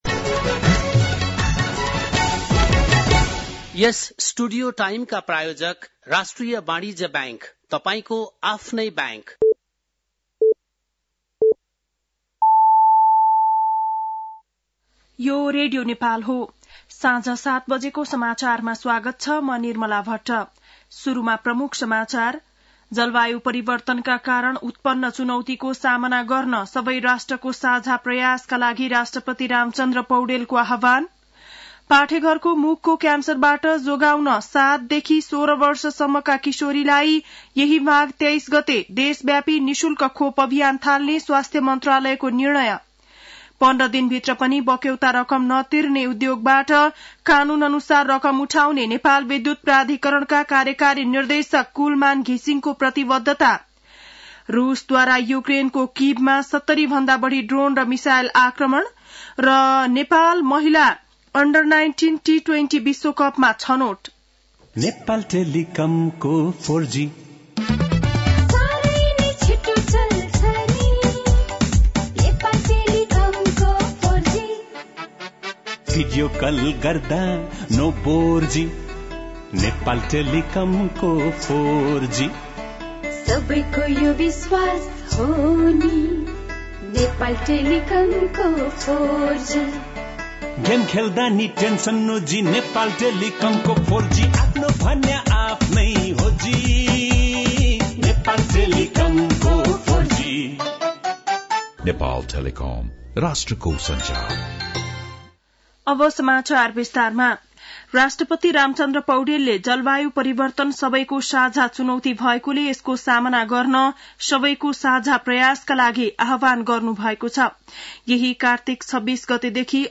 बेलुकी ७ बजेको नेपाली समाचार : २९ कार्तिक , २०८१
7-Pm-nepali-news-7-28.mp3